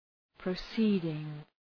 Προφορά
{prə’si:dıŋ}